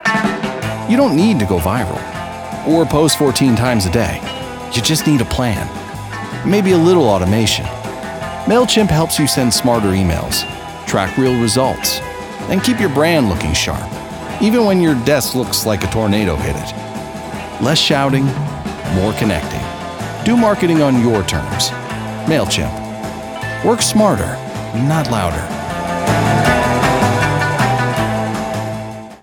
Conversational · Clever · Witty
Approachable, friendly read for SaaS and tech brands that want to sound human.